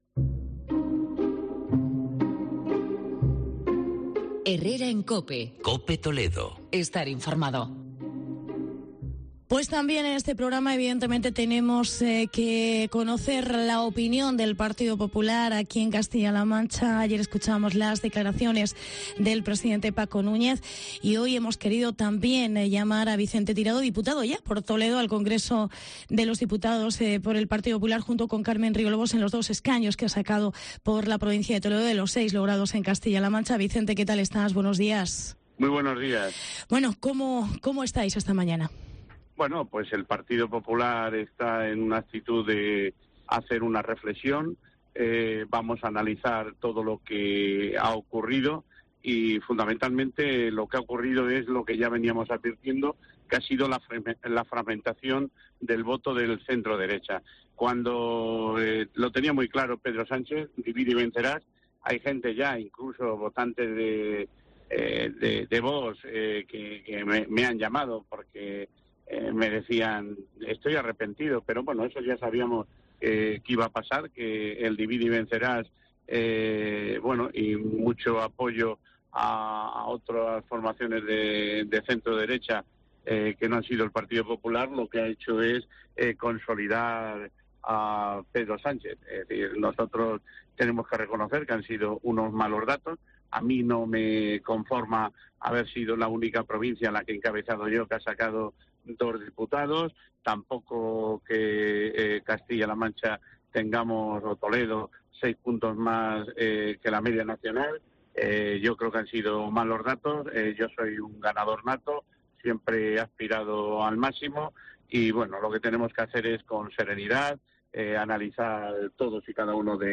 El PP pierde en CLM seis de los doce escaños conseguidos en 2016. Entrevista con Vicente Tirado